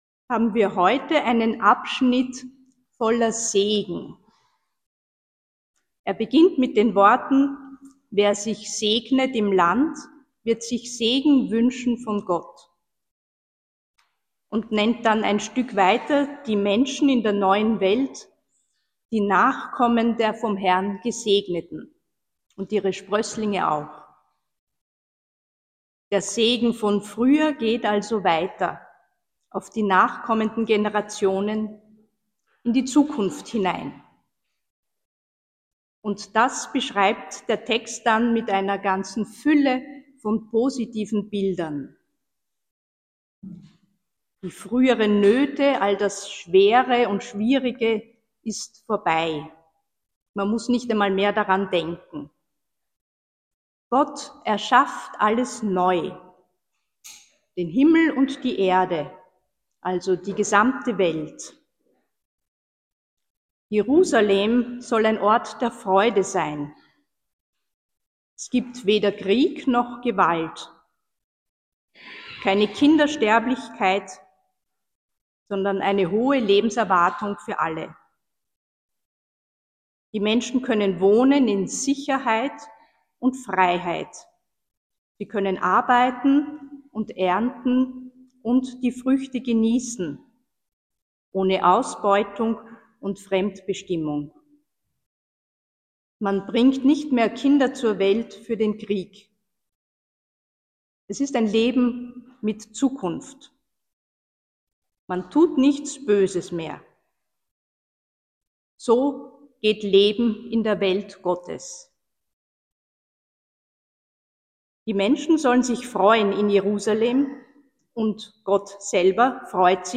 Worte zur Schrift